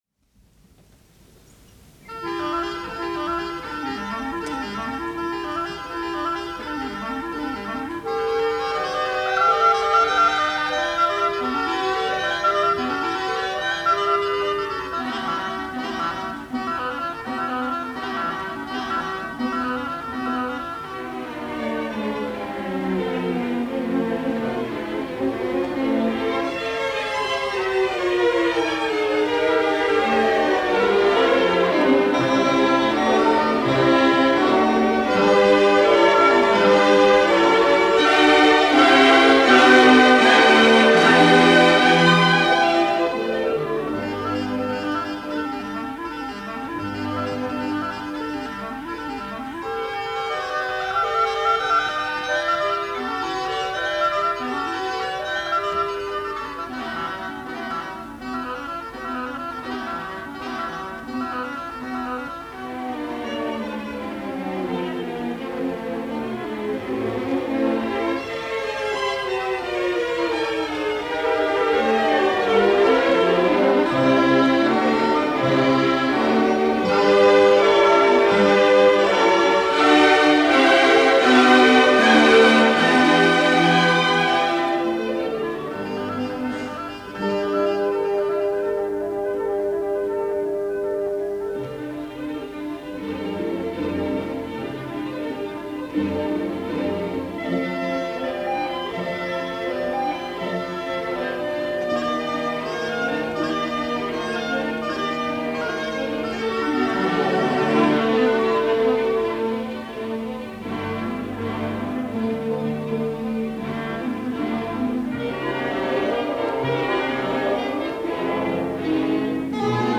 Music Of Ravel - Orchestre National de France - Ernest Bour - 1952 - Past Daily Weekend Gramophone - May 31, 1952 - ORTF, Paris.
This is reflected in the piece’s structure, which imitates a Baroque dance suite.